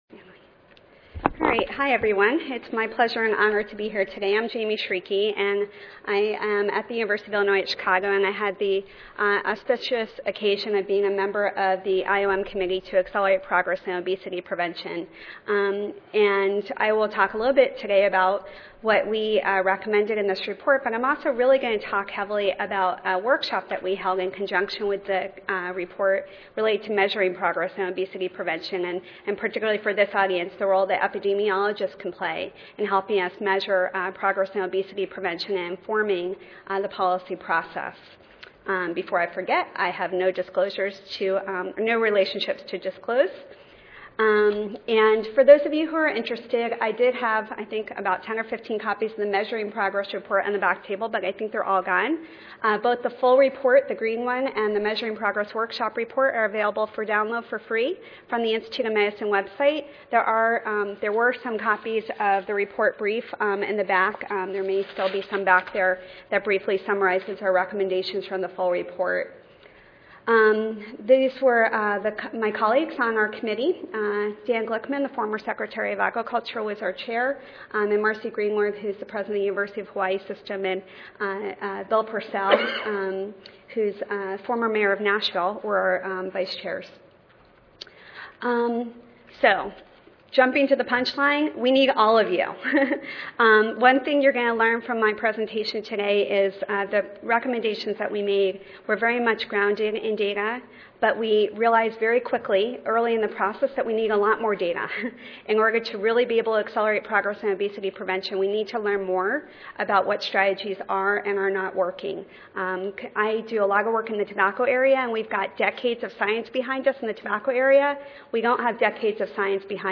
This presentation will review obesity-related trends, summarize the APOP goals and strategies for dealing with this complex and urgent problem, and will highlight roles and opportunities for epidemiologists to play in supporting efforts to evaluate progress in obesity prevention based on recommendations emanating from the Measurement workshop and based on indicators of progress identified by the APOP Committee.